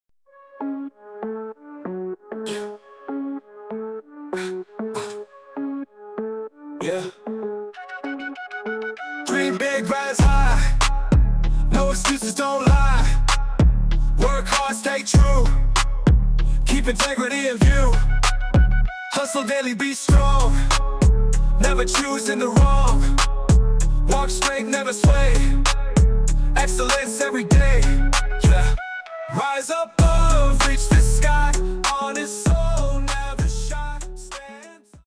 Short version of the song, full version after purchase.
An incredible Hip Hop song, creative and inspiring.